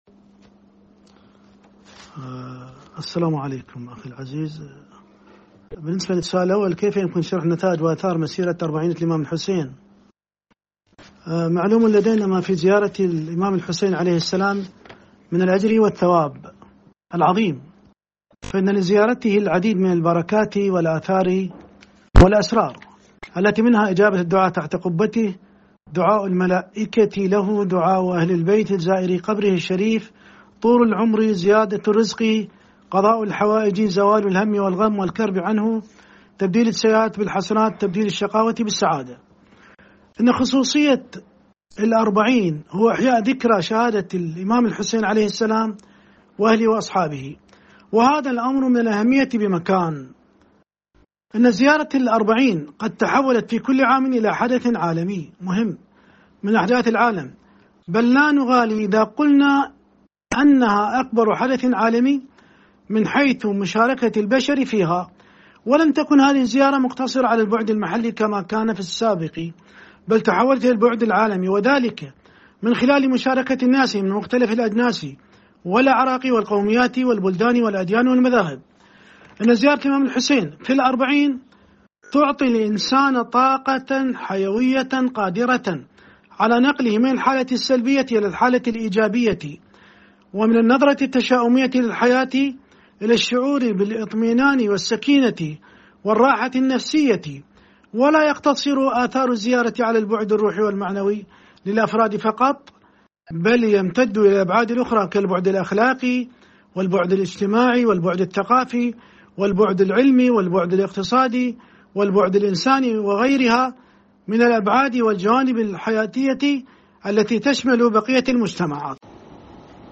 في حديث خاص له مع وكالة الأنباء القرآنية الدولية(إکنا)